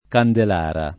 vai all'elenco alfabetico delle voci ingrandisci il carattere 100% rimpicciolisci il carattere stampa invia tramite posta elettronica codividi su Facebook Candelara [ kandel # ra ] e Candelaia [ kandel #L a ] → Candelora